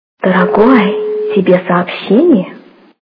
» Звуки » звуки для СМС » Женский голос - Дорогой тебе СМС
При прослушивании Женский голос - Дорогой тебе СМС качество понижено и присутствуют гудки.
Звук Женский голос - Дорогой тебе СМС